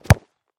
Удар по мячу средней мощности